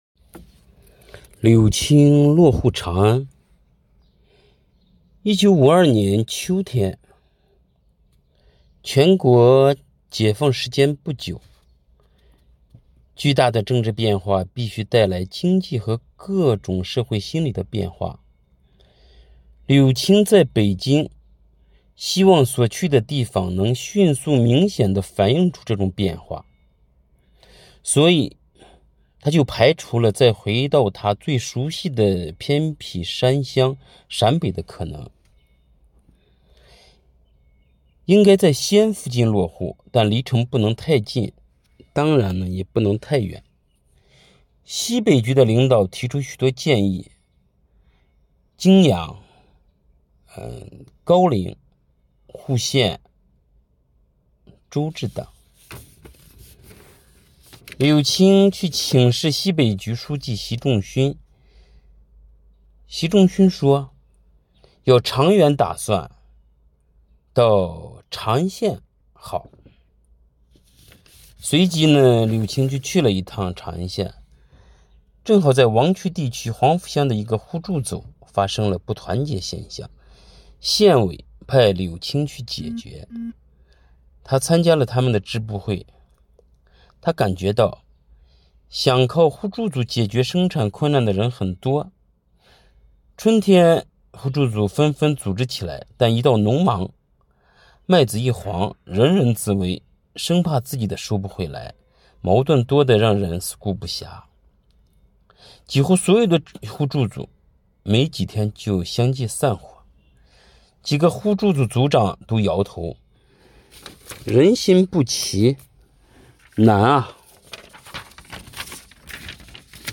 【红色档案诵读展播】柳青落户长安